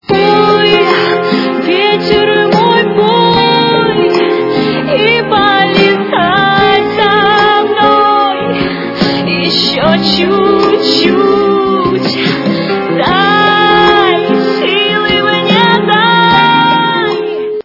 русская эстрада
При заказе вы получаете реалтон без искажений.